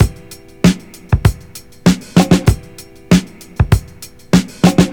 • 97 Bpm Drum Loop Sample E Key.wav
Free drum beat - kick tuned to the E note. Loudest frequency: 1023Hz
97-bpm-drum-loop-sample-e-key-p8t.wav